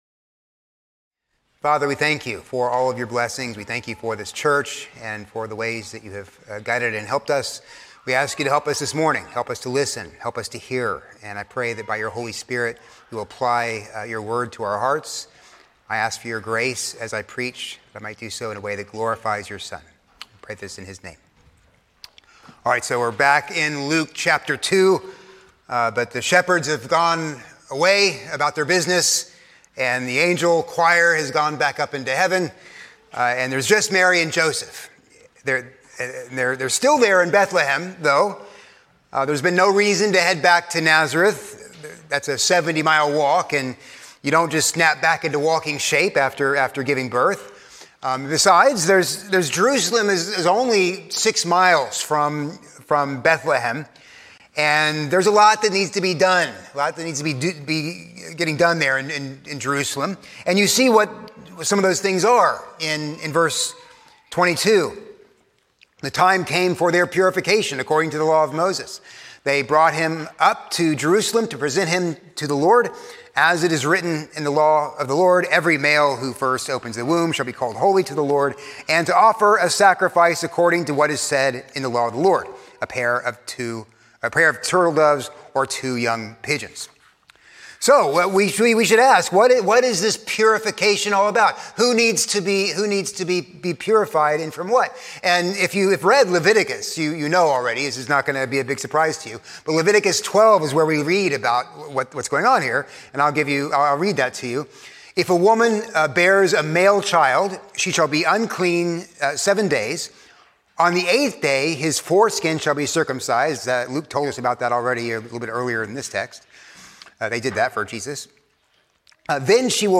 A sermon on Luke 2:22-35